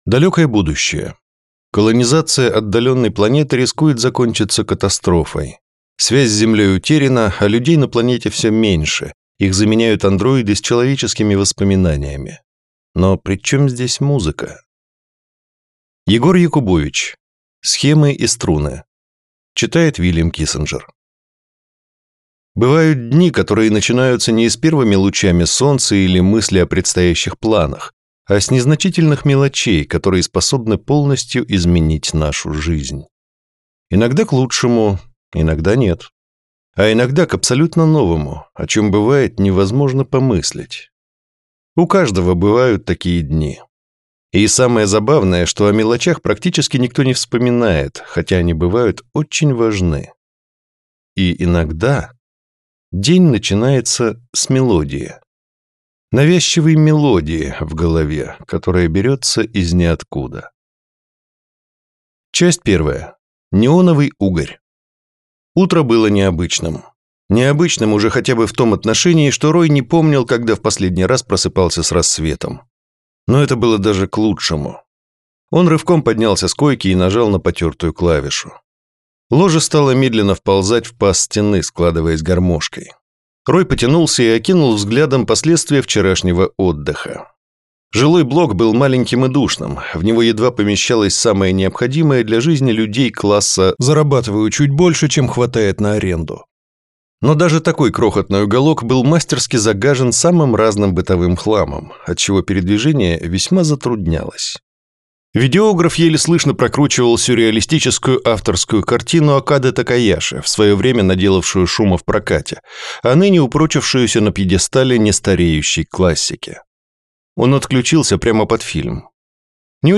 Аудиокнига Схемы и струны | Библиотека аудиокниг